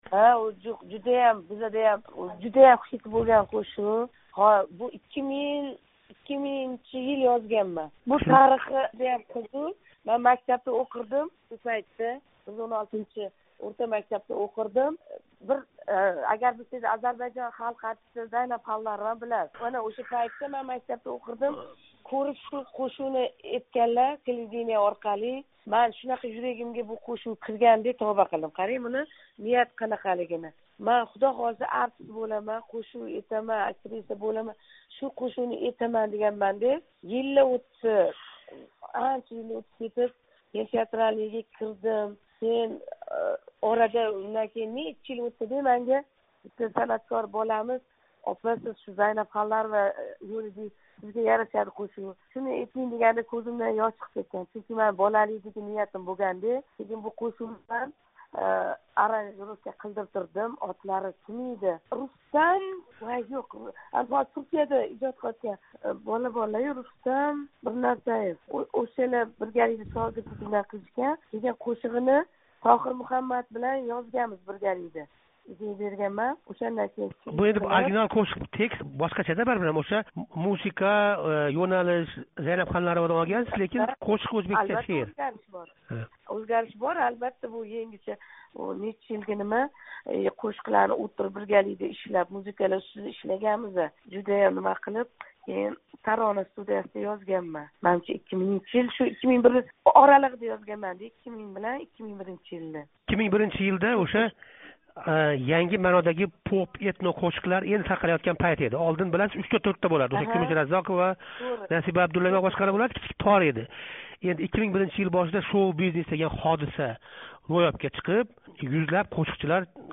Дилфуза Исмоилова билан суҳбат